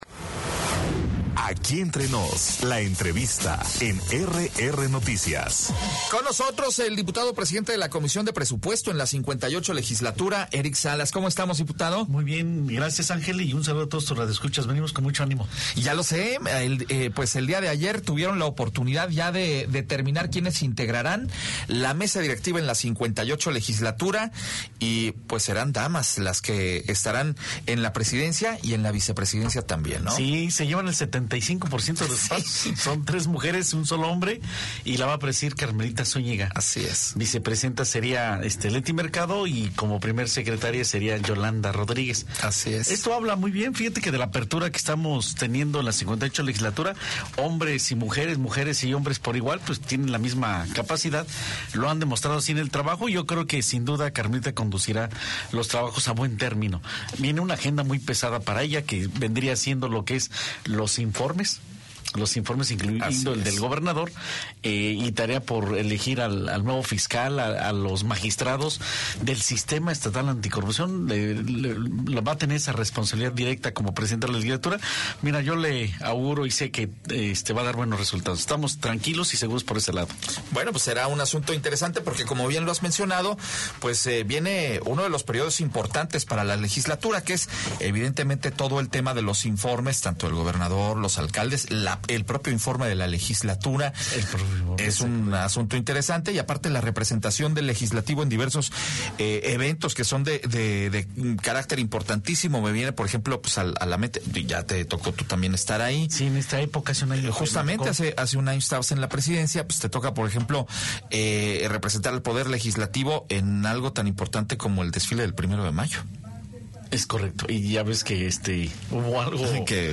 La entrevista a Eric Salas, sobre su iniciativa para reducir el número de diputados - RR Noticias